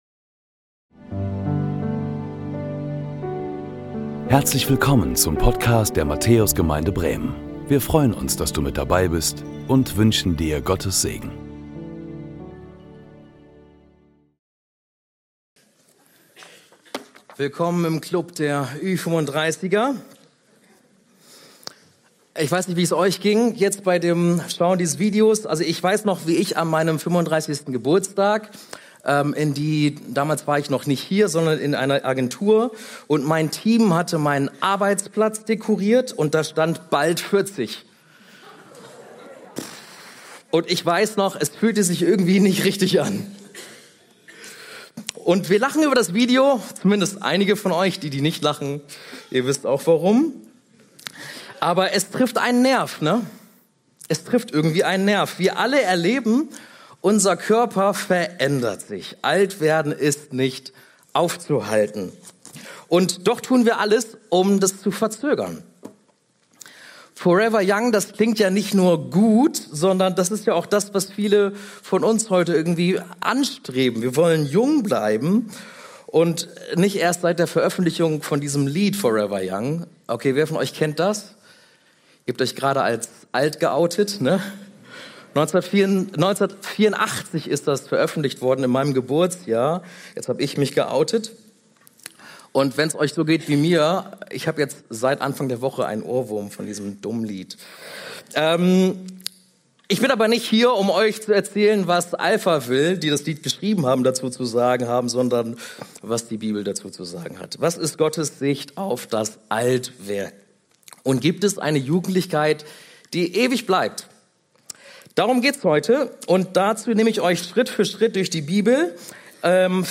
Predigten der Matthäus Gemeinde Bremen Forever Young Play Episode Pause Episode Mute/Unmute Episode Rewind 10 Seconds 1x Fast Forward 30 seconds 00:00 / 25:54 Abonnieren Teilen Apple Podcasts RSS Spotify RSS Feed Teilen Link Embed